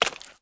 STEPS Swamp, Walk 28.wav